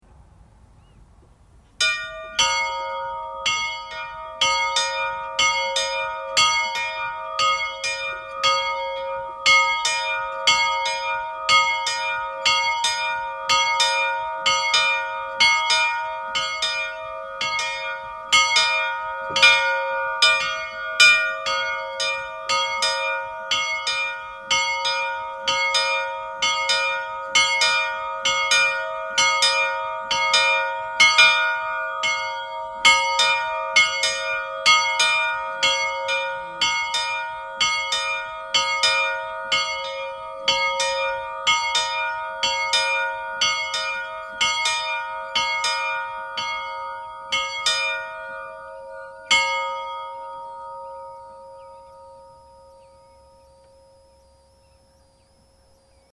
Reyniskirkja - Kirkjuklukkur Íslands
reyniskirkja_vixl.mp3